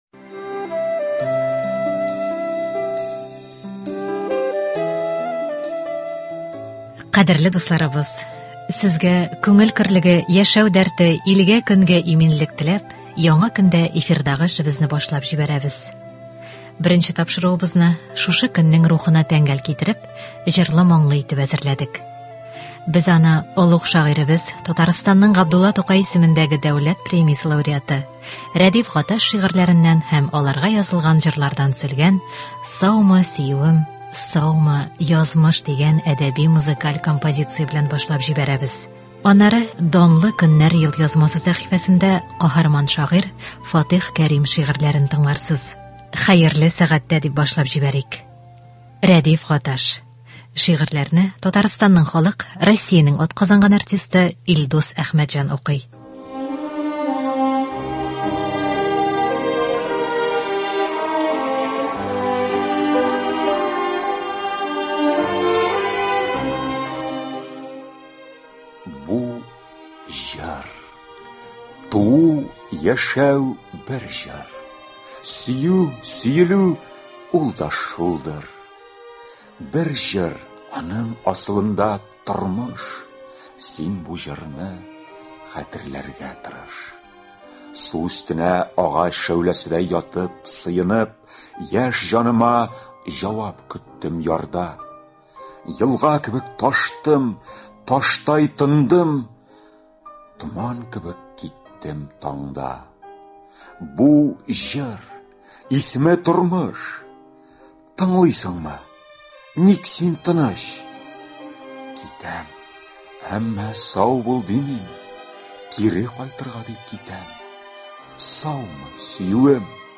Рәдиф Гаташ әсәрләреннән әдәби-музыкаль композиция.